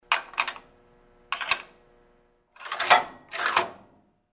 Throwing coins into the machine
Sound category: Money, coins